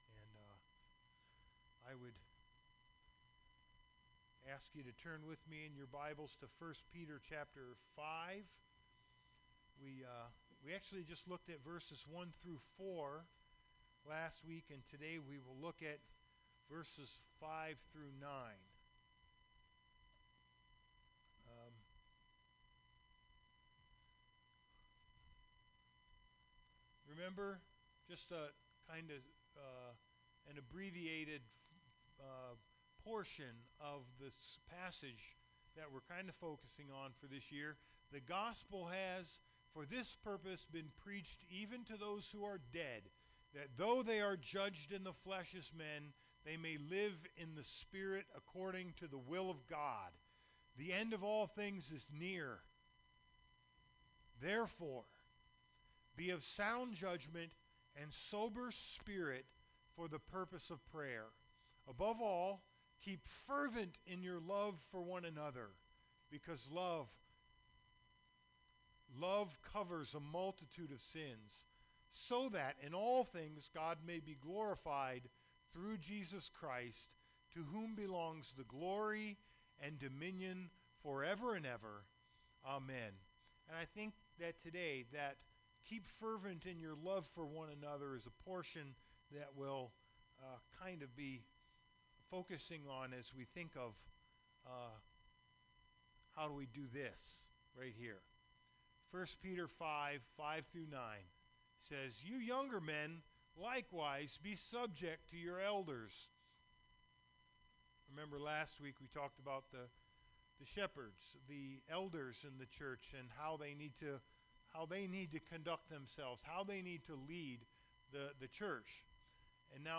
Gethsemane Covenant Church Archived Sermons